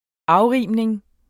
Udtale [ -ˌʁiˀmnəŋ ]